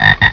FROG.WAV